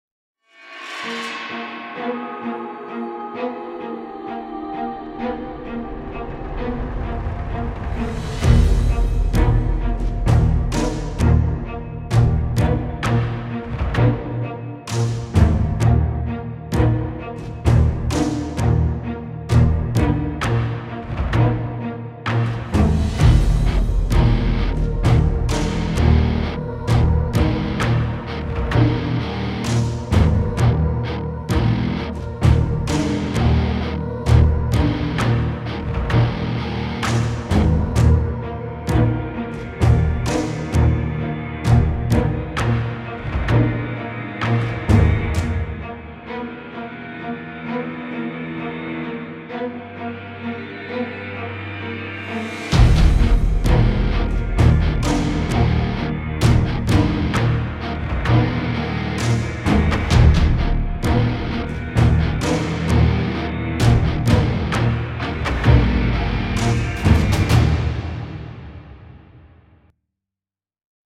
Was a cutscene background track from a game that was scrapped into oblivion lmao
orchestral guitar choir soundtrack game music